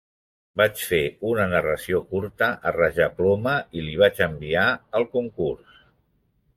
Pronounced as (IPA) [ˈkur.tə]